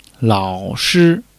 lao3--shi1.mp3